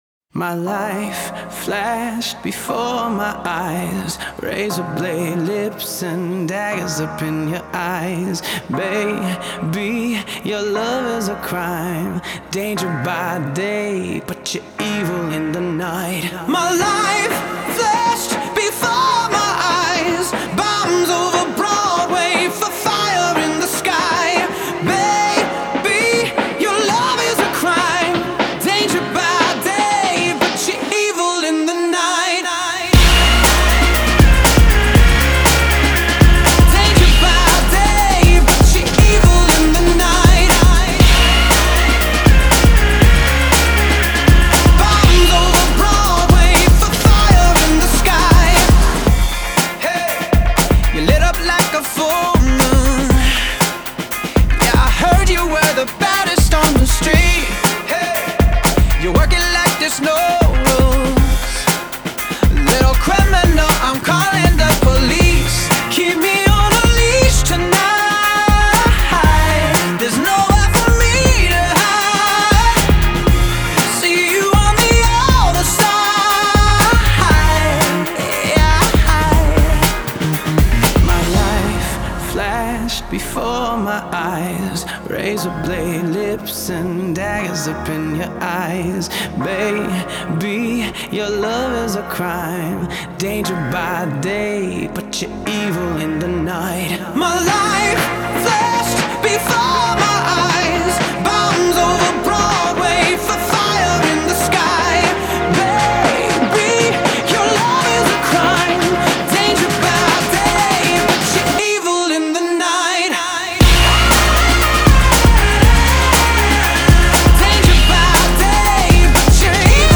Genre: Pop, Dance